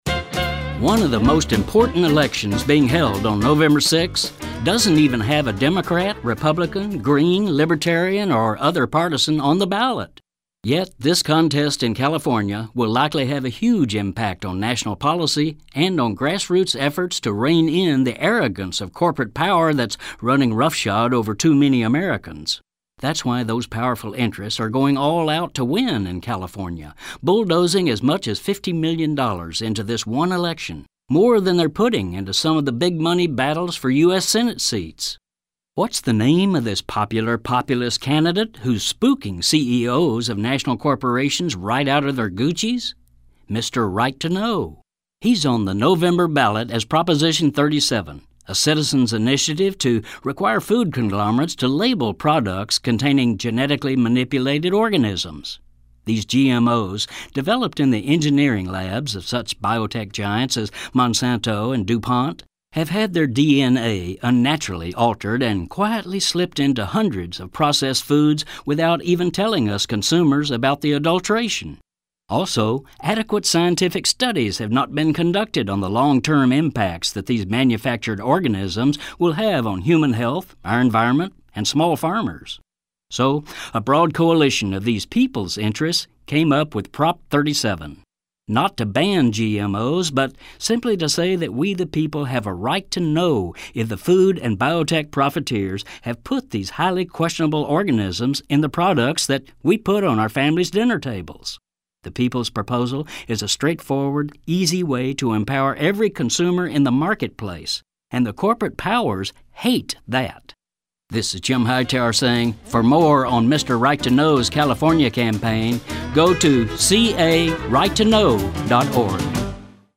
listen to a Common Sense Commentary from Jim Hightower or visit California's Right to Know website.